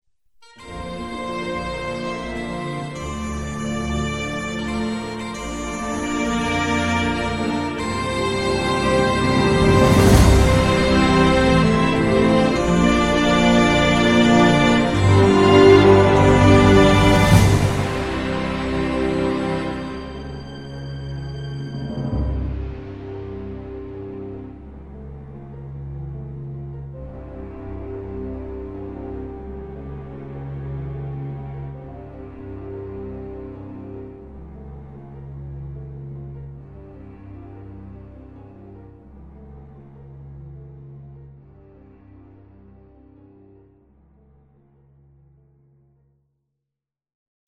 Orchestral mix of traditional and Celtic instruments.